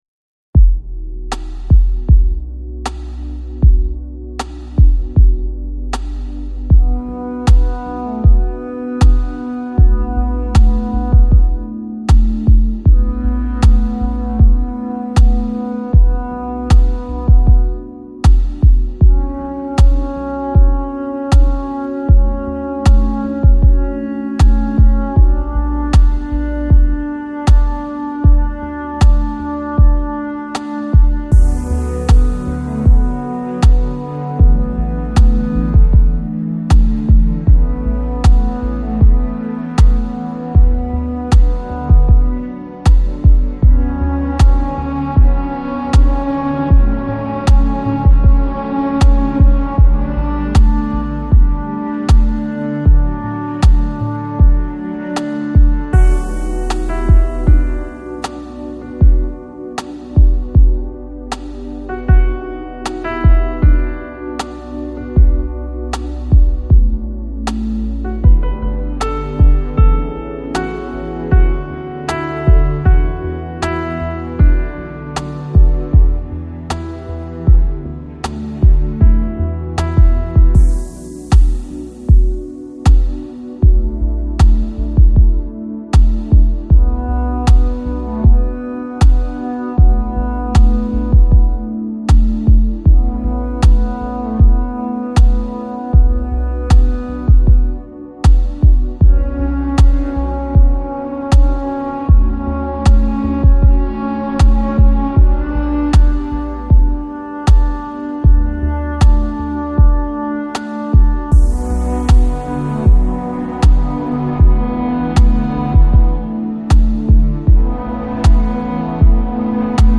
Жанр: Electronic, Lounge, Chill Out